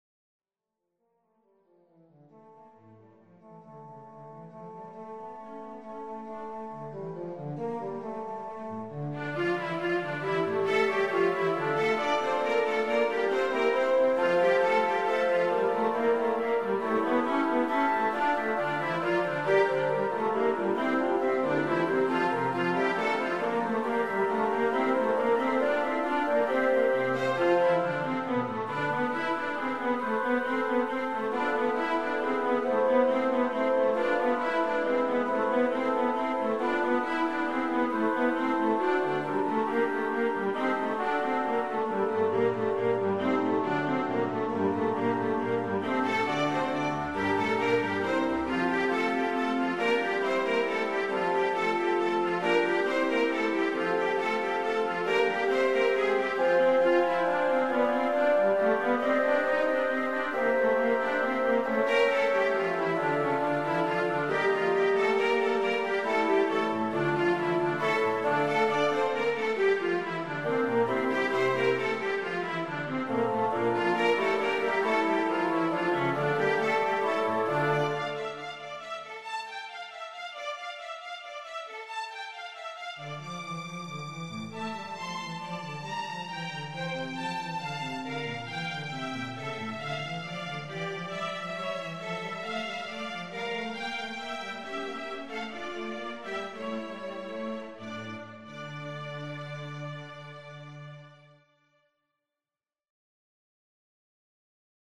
(SSATB and Soli).
(Chorus and Soli)